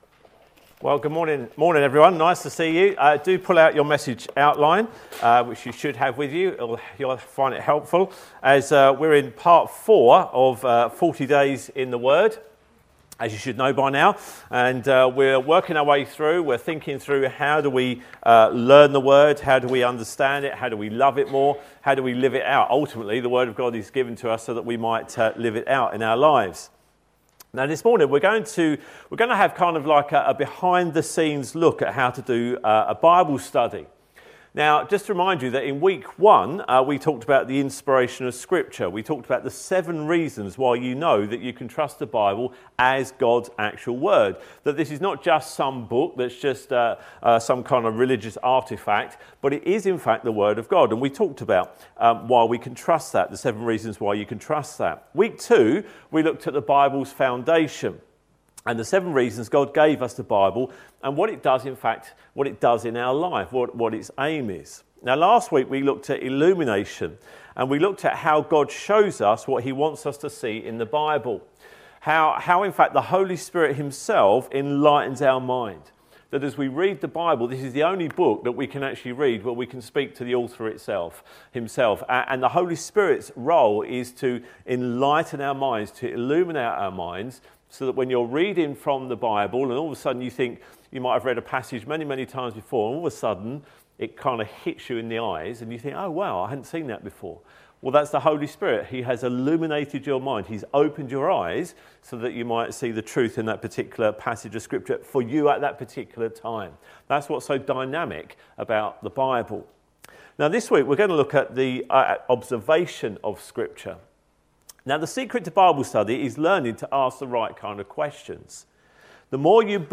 In the message this morning we are going to have a behind the scenes look at how to do a Bible study.